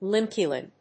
アクセント・音節líme・kìln